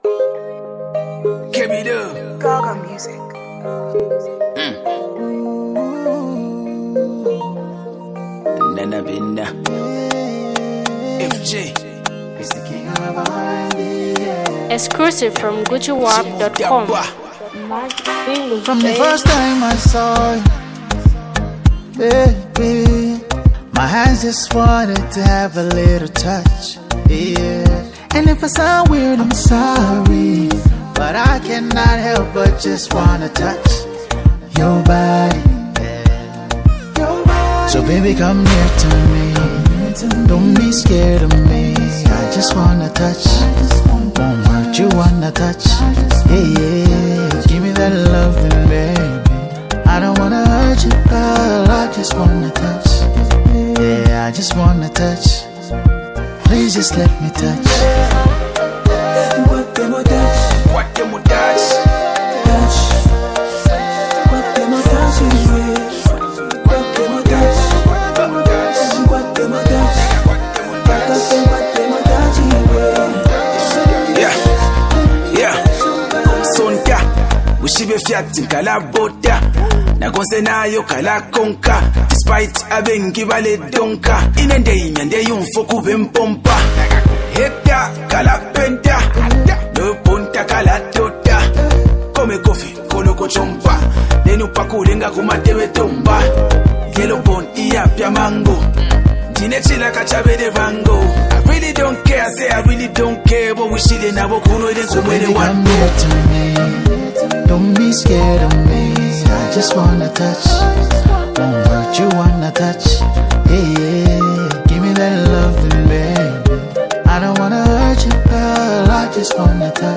Zambian Mp3 Music
irresistible melody